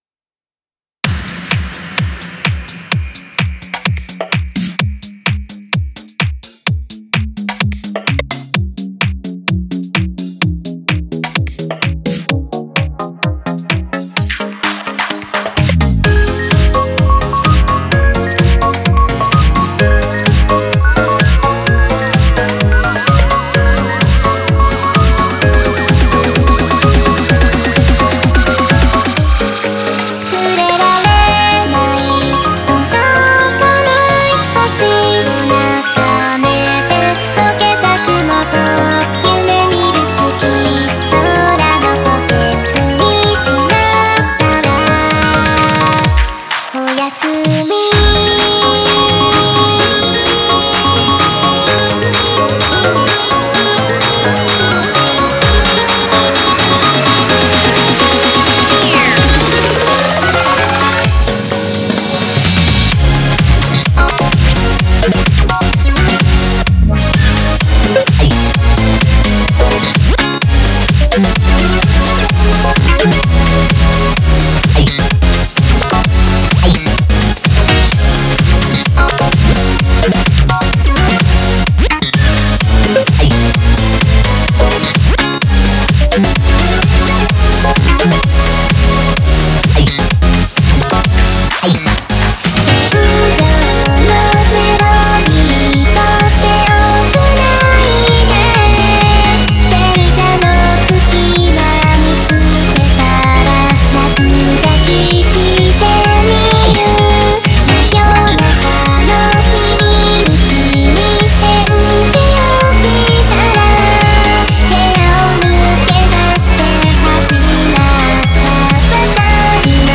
data/music/Japanese/J-Core